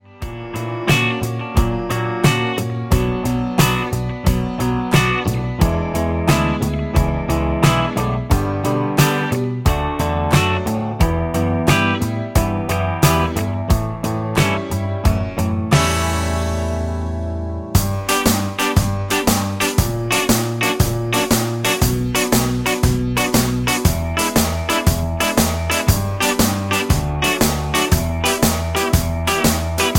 D
Backing track Karaoke
Pop, Oldies, 1960s